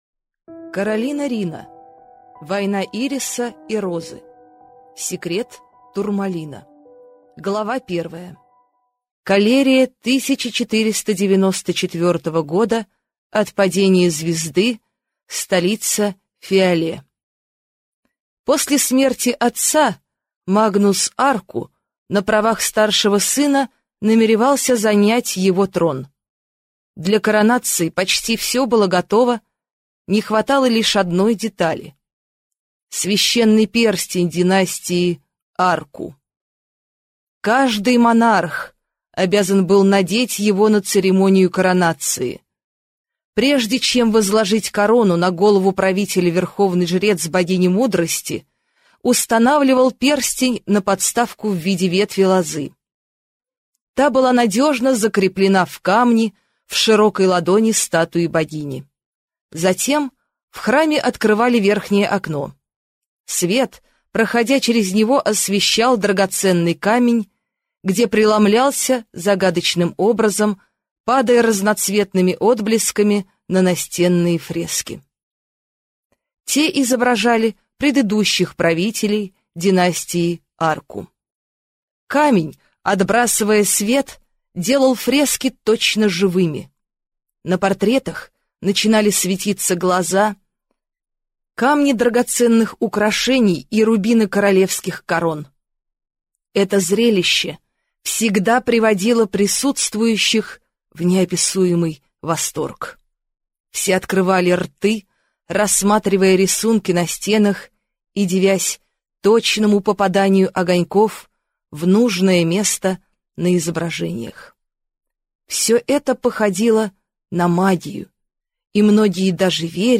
Аудиокнига Война Ириса и Розы. Секрет турмалина | Библиотека аудиокниг